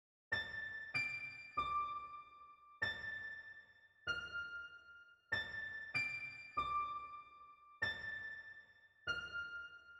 深邃的真理 温暖的小提琴 96 BPM
描述：在FL Studio中使用Edirol Orchestral创作。
标签： 96 bpm Dancehall Loops Violin Loops 1.68 MB wav Key : Unknown
声道立体声